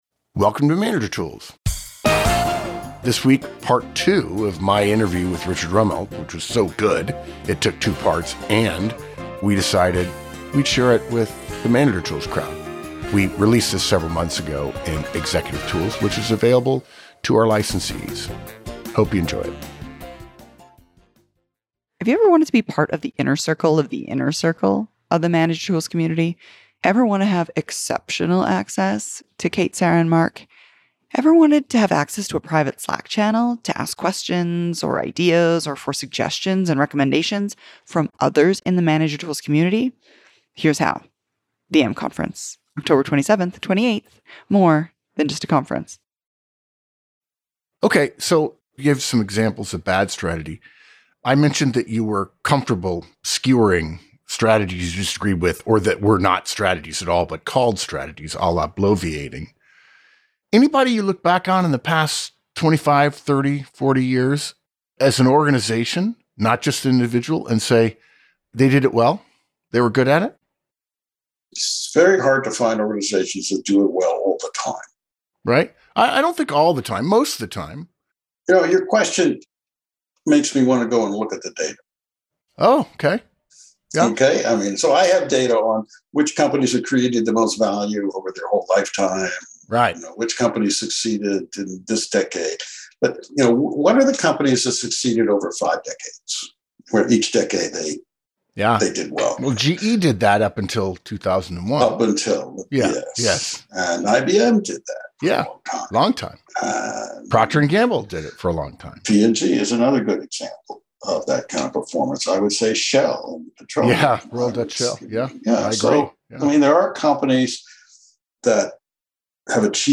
Part 2 of our interview with Richard Rumelt, strategy professor at UCLA Anderson and author of Good Strategy/Bad Strategy.